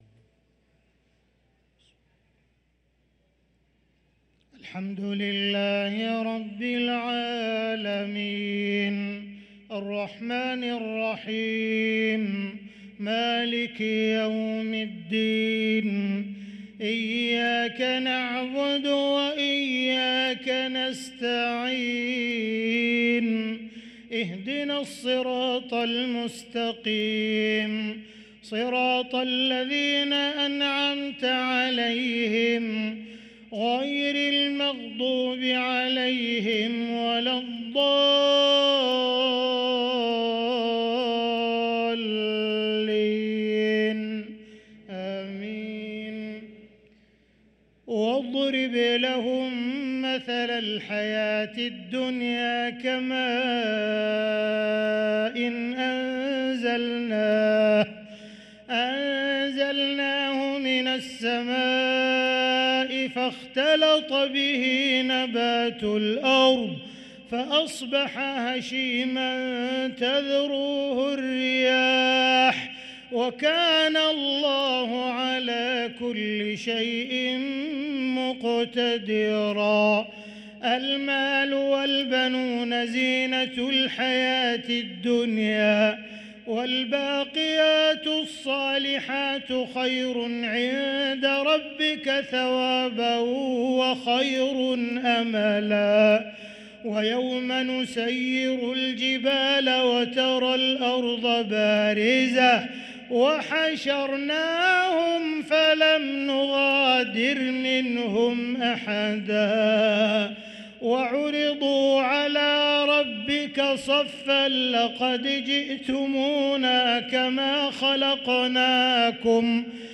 صلاة العشاء للقارئ عبدالرحمن السديس 14 ربيع الأول 1445 هـ
تِلَاوَات الْحَرَمَيْن .